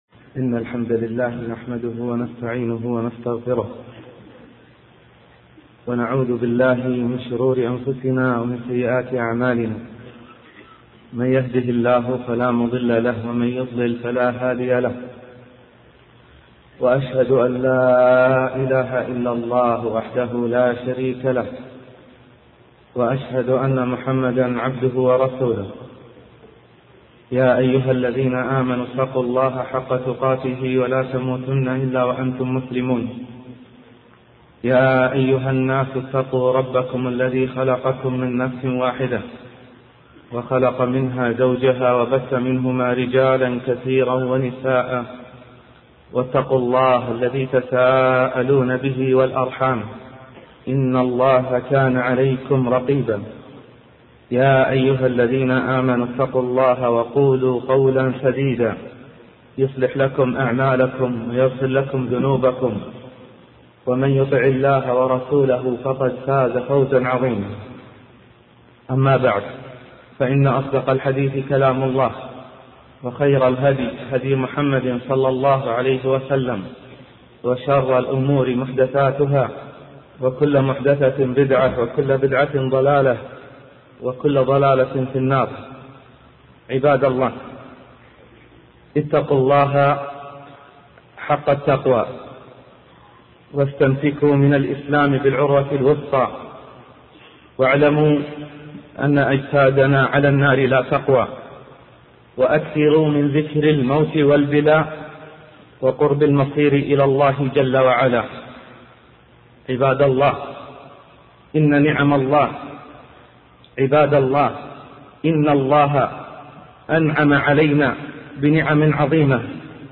الملخص: محاضرة مفاتيح البركة تركز على أن البركة بيد الله وحده، ومن أهم أسبابها: تقوى الله: باب الرزق والفرج.